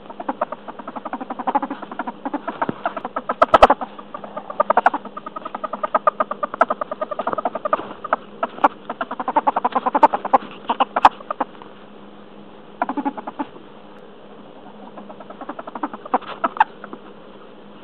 На этой странице собраны разнообразные звуки хорьков: от игривого попискивания до довольного урчания.
Звуки, которые издают милые хорьки